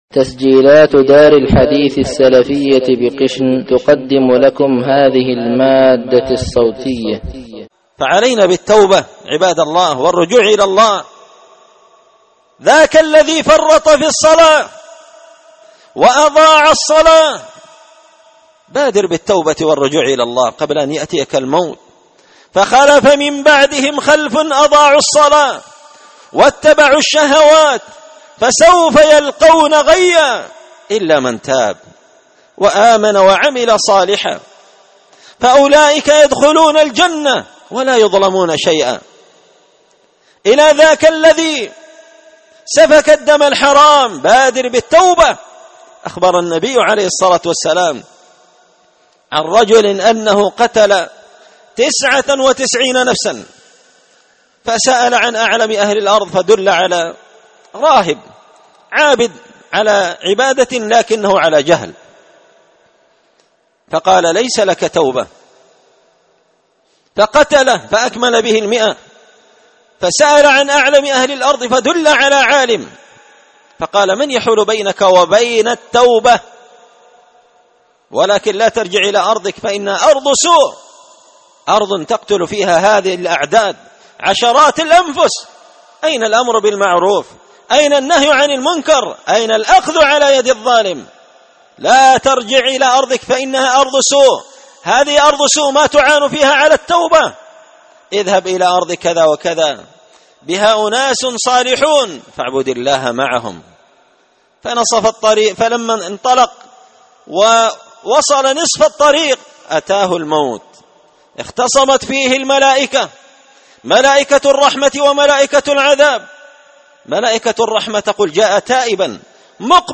خطبة جمعة بعنوان – المبادرة بالتوبة قبل حلول العقوبة
دار الحديث بمسجد الفرقان ـ قشن ـ المهرة ـ اليمن